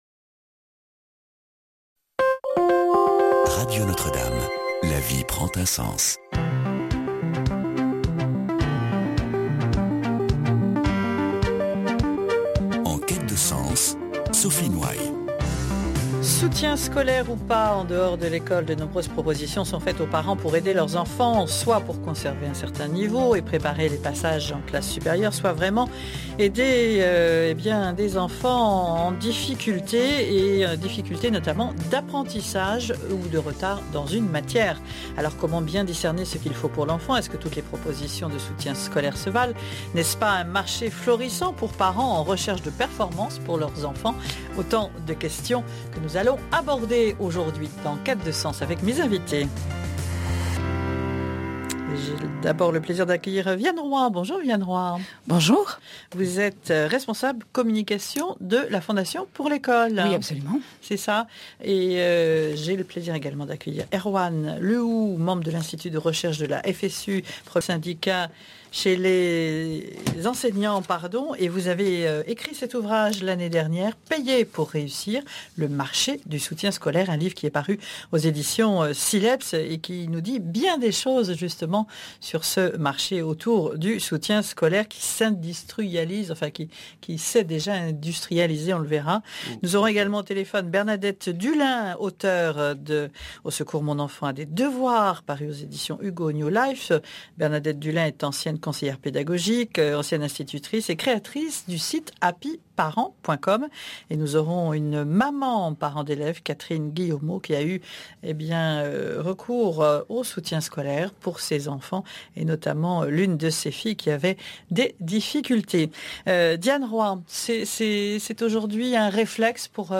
L'accompagnement scolaire et familial » Blog Archive » Radio Notre Dame, en quête de sens : le soutien scolaire est-il efficace pour les élèves en difficulté ? Débat